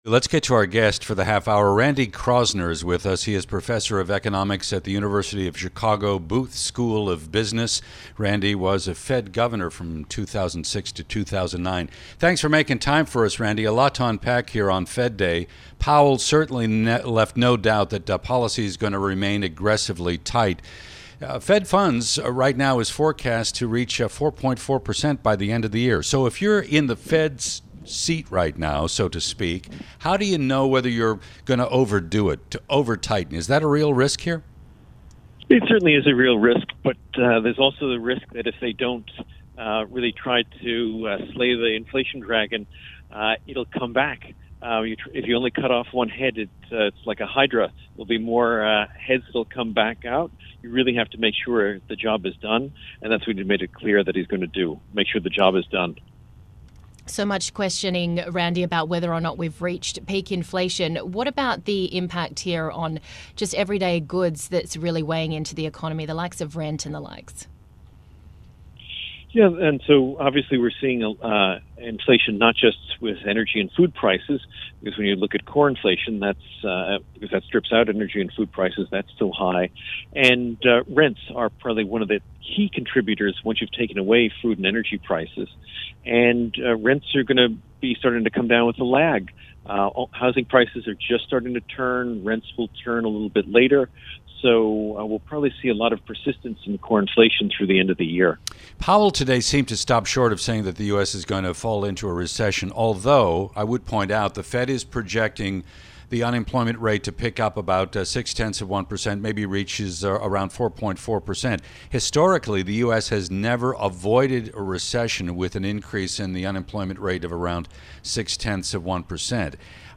Randy Kroszner, Professor of Economics at University of Chicago Booth School of Business, to talk on Fed and markets.